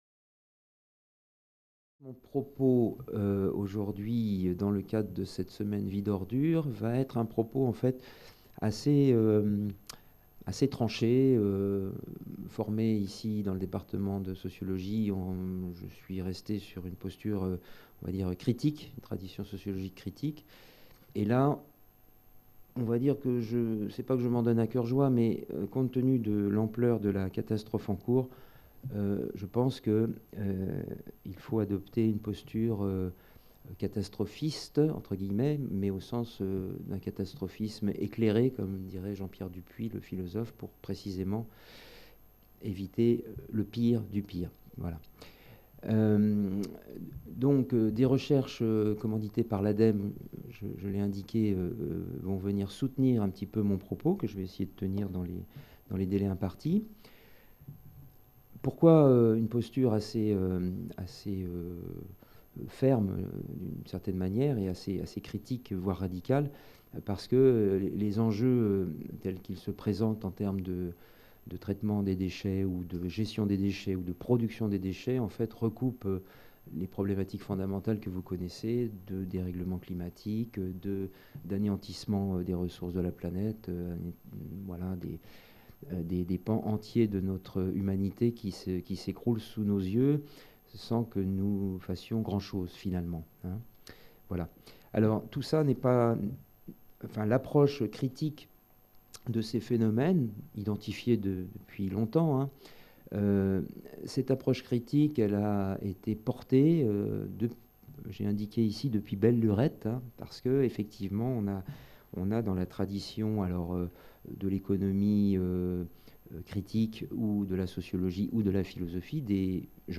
Cette communication a été filmée lors de la série d'événements organisé par la MRSH autour de la question des déchets, abordant les enjeux écologiques qui se posent à nos sociétés.